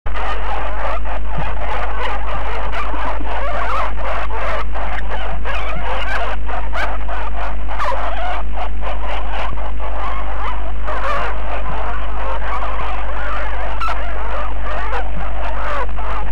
На этой странице собраны разнообразные звуки хорьков: от игривого попискивания до довольного урчания.
Хорьчиха с потомством